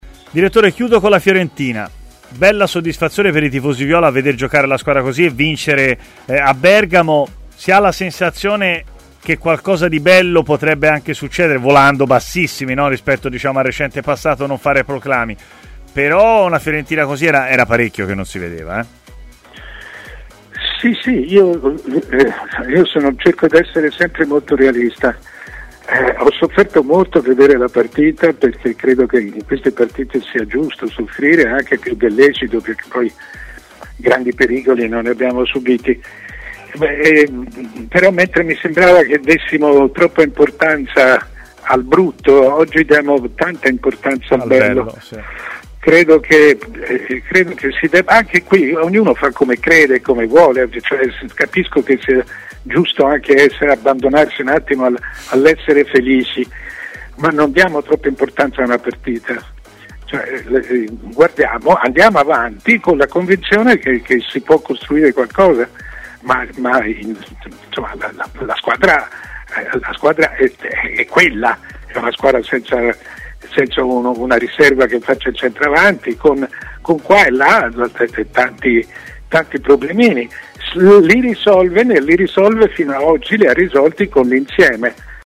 L'opinionista Mario Sconcerti è intervenuto in diretta a Stadio Aperto, trasmissione di TMW Radio.